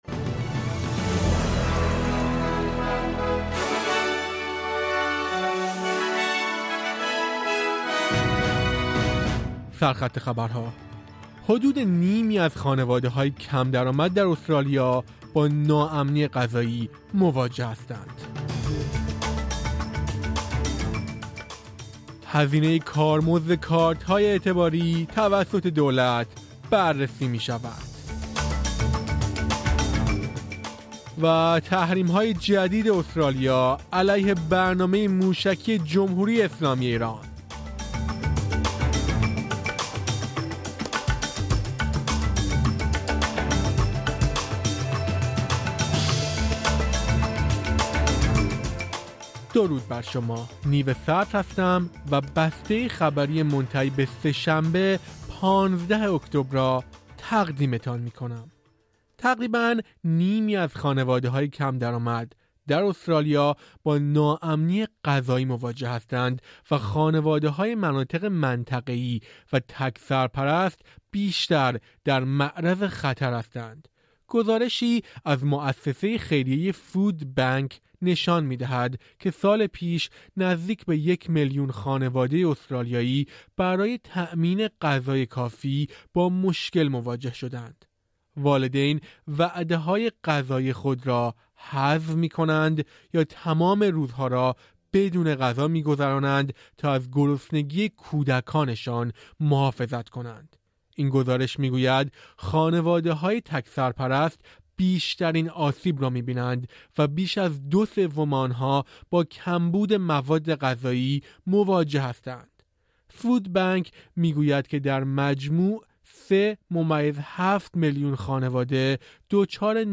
در این پادکست خبری مهمترین اخبار استرالیا، جهان و ایران در یک هفته منتهی به سه‌شنبه ۱۵ اکتبر ۲۰۲۴ ارائه شده است.